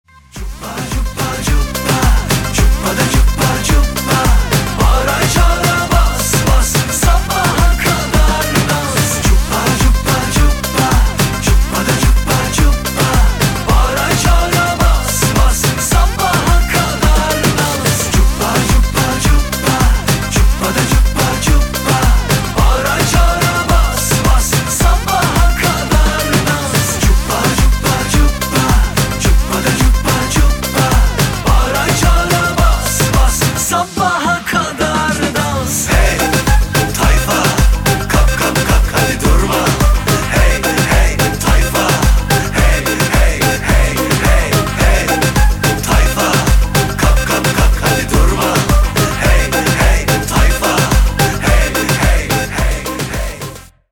поп
мужской голос
восточные мотивы
dance
энергичные
быстрые
подвижные